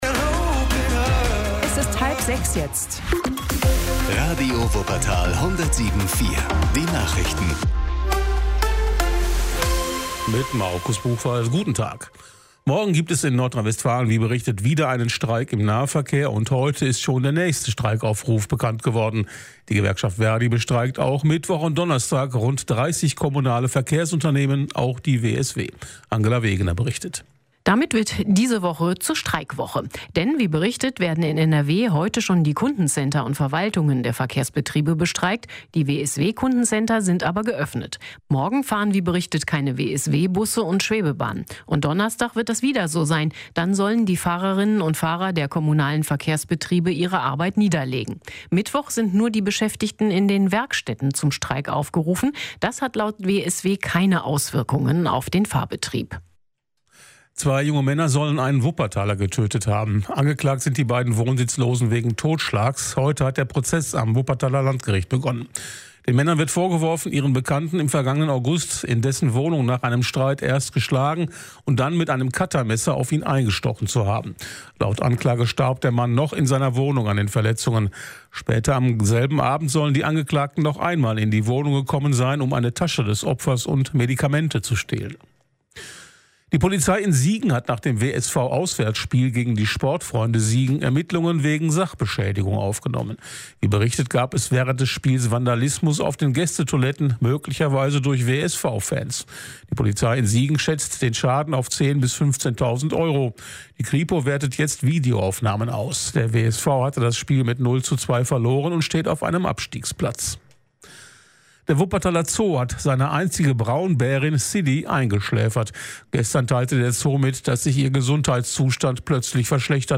Unser Service zum Nachhören: Die jeweils letzte und aktuellste Ausgabe der Radio Wuppertal Lokalnachrichten in voller Länge.
Lokalnachrichten.mp3